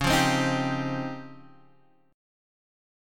C#M#11 chord